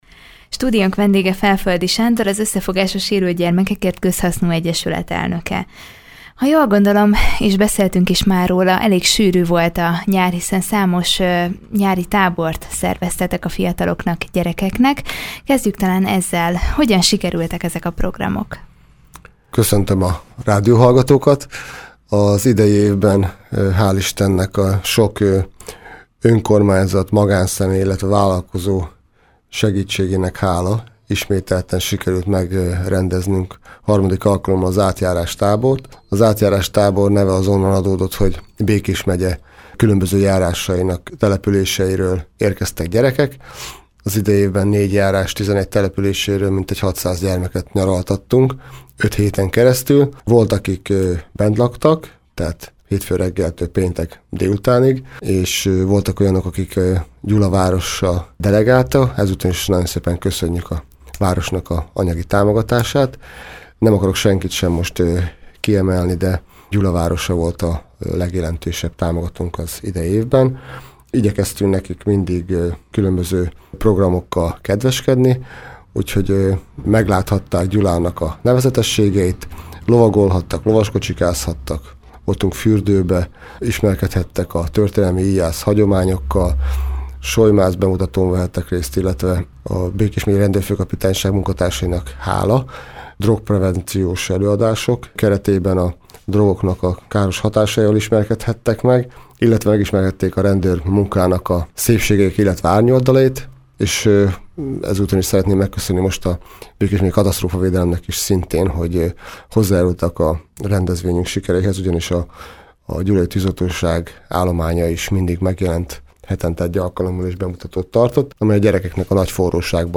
Október végén immár ötödik alkalommal kerül megrendezésre Gyulán a Halloween futás az az Összefogás a Sérült Gyermekekért Közhasznú Egyesület szervezésében. Ezzel kapcsolatban beszélgetett tudósítónk